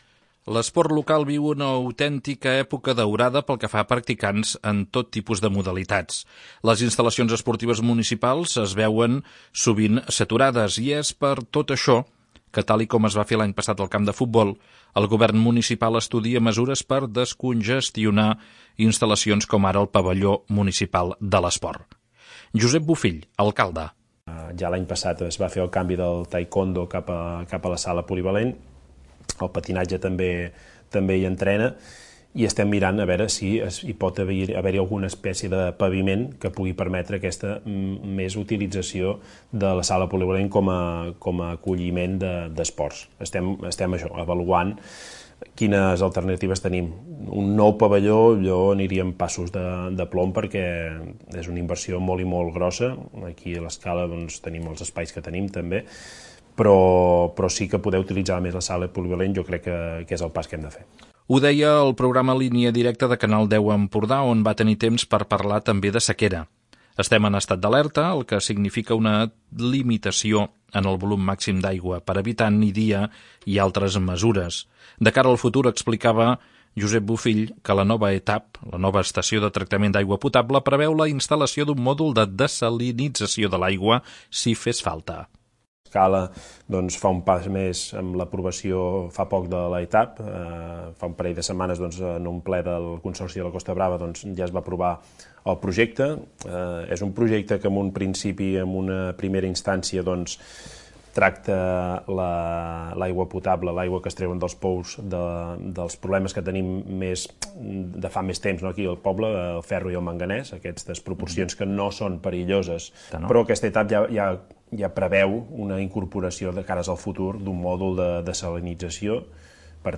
L'alcalde ho explica al programa Línia Directa, on parla també de mesures d'adaptació a la sequera o la consolidació de la gestió directa a la piscina municipal.